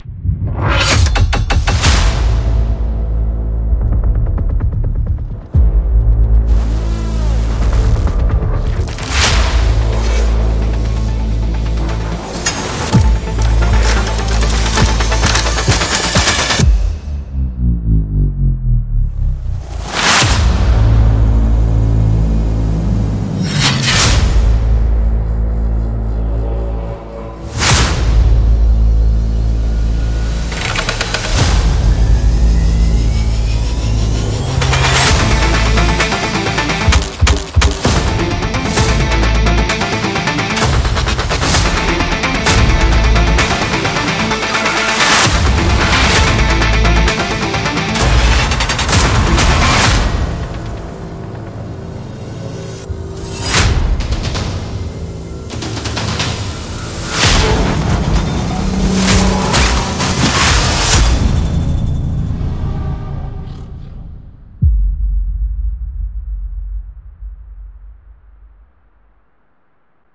【电影&机械引擎FX风格KONTAKT扩展+采样】Keepforest Risenge Pro
从原始材料的录制到最终产品，他们都确保了世界各地的音乐制作人手中都将拥有最高质量的电影声音库。
八层模块可以轻松地进行随机，组合和修改，拥有4000多种经过全面设计的上升，倒吸，击打，口吃，经典滴落和分层以及混合，引擎和马达，机械，管弦乐，科幻和恐怖的声音，以及功能强大且易于使用的音序器，使您能够立即为您的构图创建紧张而富有表现力的电影作品。